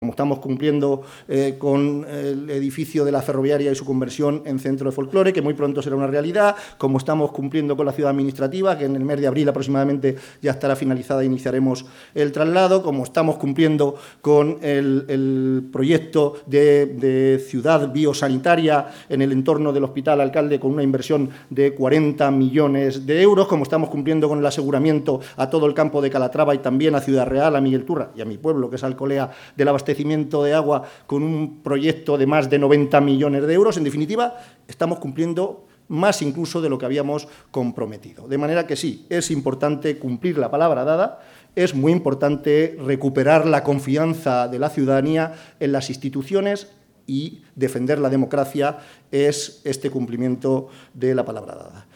>> Desde la inauguración de la pasarela ciclopeatonal que une Ciudad Real y Miguelturra